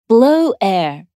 blowair.mp3